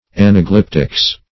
Search Result for " anaglyptics" : The Collaborative International Dictionary of English v.0.48: Anaglyptics \An`a*glyp"tics\, n. The art of carving in low relief, embossing, etc. [1913 Webster]